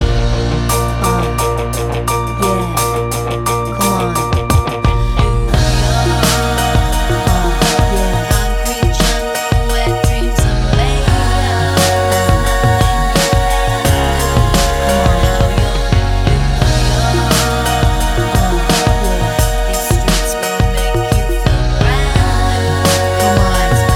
[Duet Version] R'n'B / Hip Hop 4:35 Buy £1.50